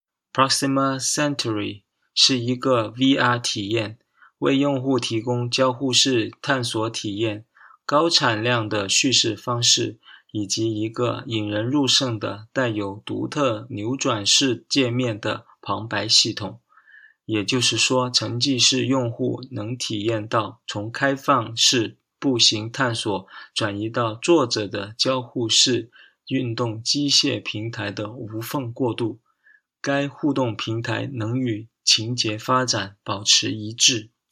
The Audio Guides are the voice recordings of the Virtual & Augmented Reality (VR/AR) contributions in different languages!
Audio Guides